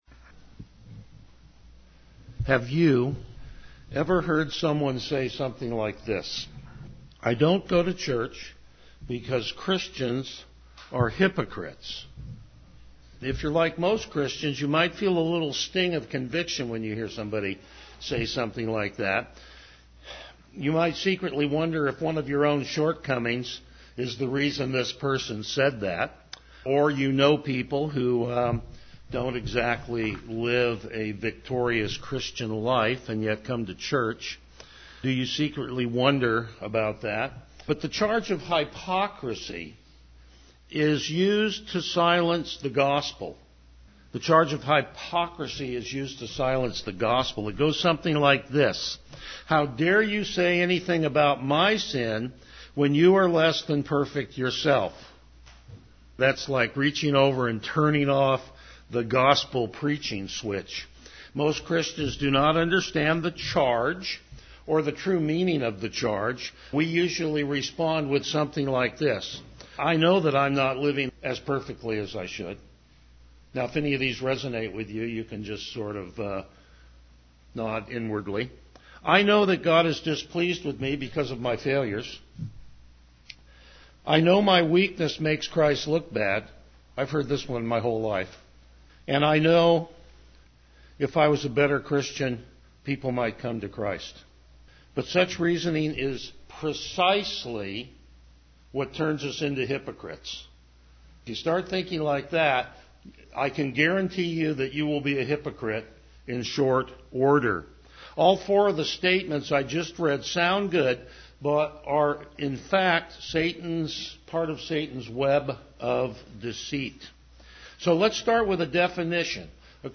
Passage: Hebrews 11:20-22 Service Type: Morning Worship
Verse By Verse Exposition